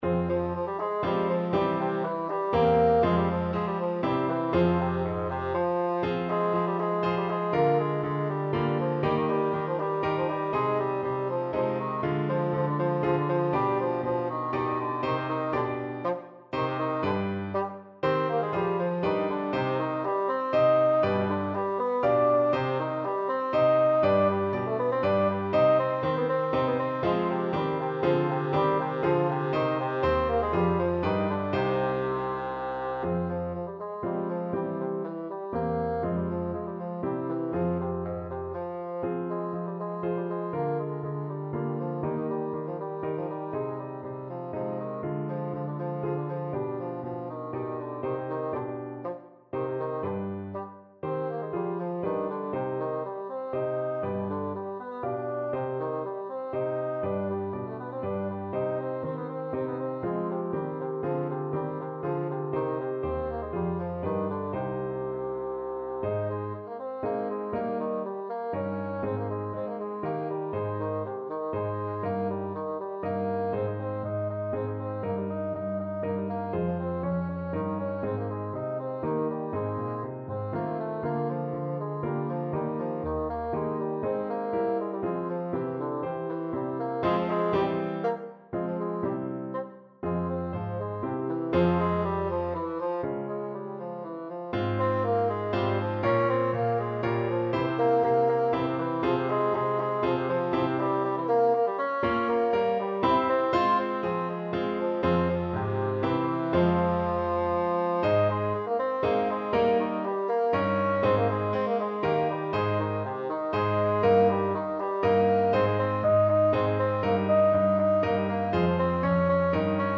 =120 Vivace (View more music marked Vivace)
3/8 (View more 3/8 Music)
Ab3-F5
Classical (View more Classical Bassoon Music)